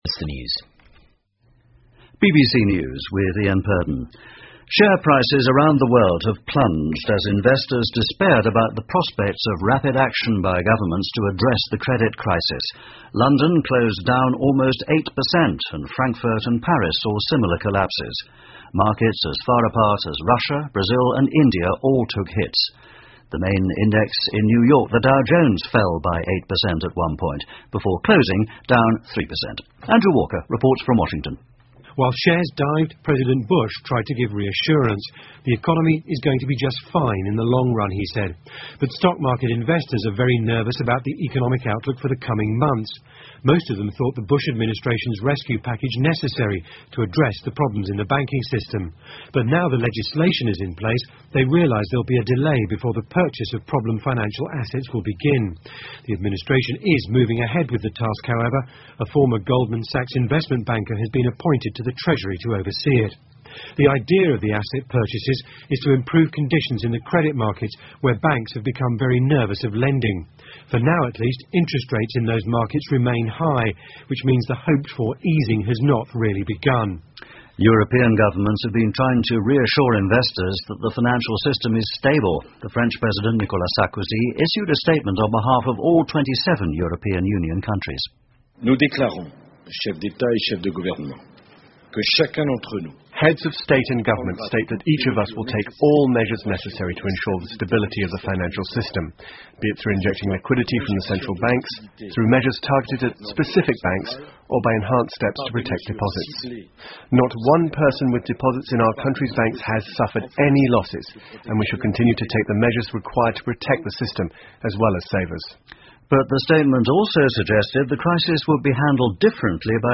英国新闻听力 07 听力文件下载—在线英语听力室